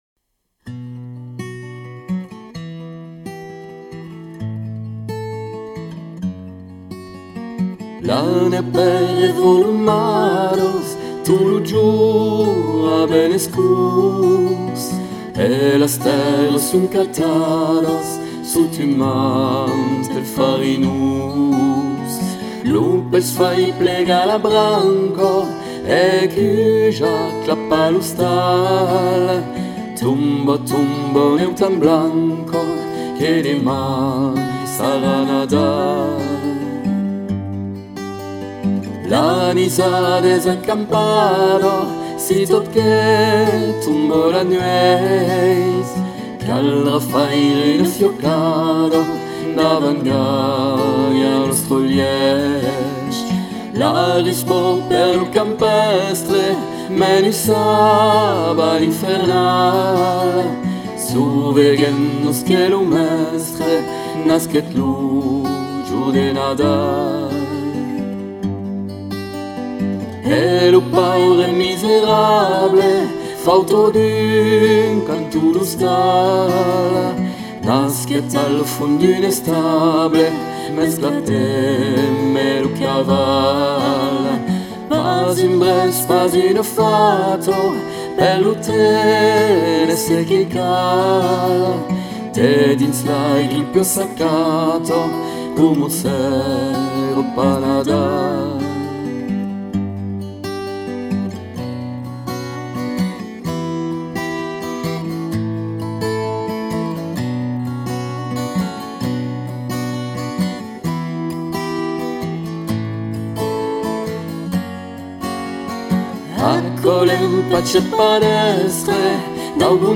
Dans quelques jours Noël Une magnifique chanson interprétée par 2 voix Cévenoles splendides, découvertes à Lavercantière lors de la commémoration du centenaire de la Grande Guerre au monument aux morts, portant l’inscription Paoures Dròles.